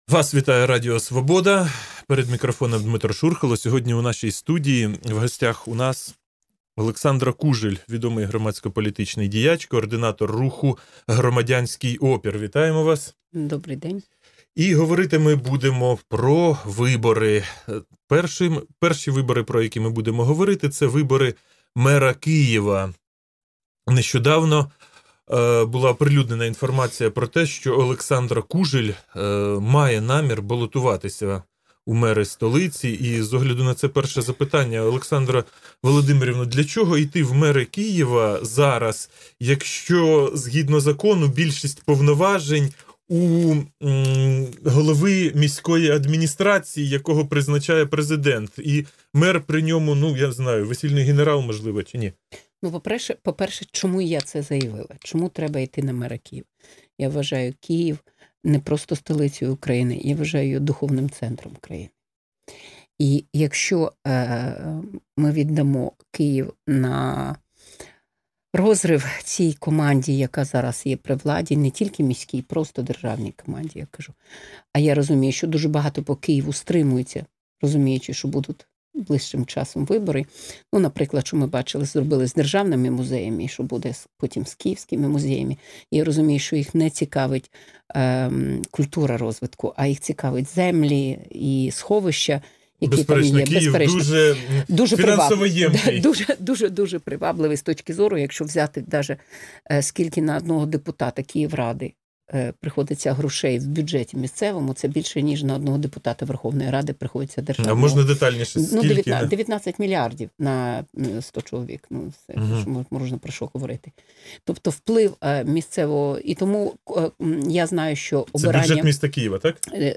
Інтерв'ю з Олександрою Кужель